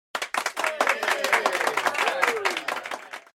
clap-AxCMZLTd.ogg